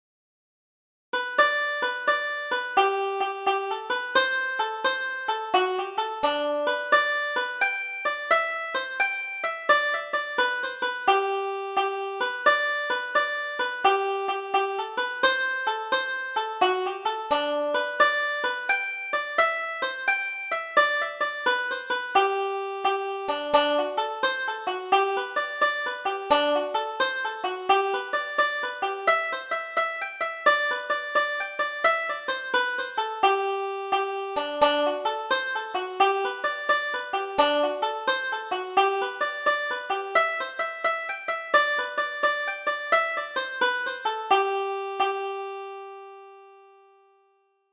Double Jig: The Kilkenny Girl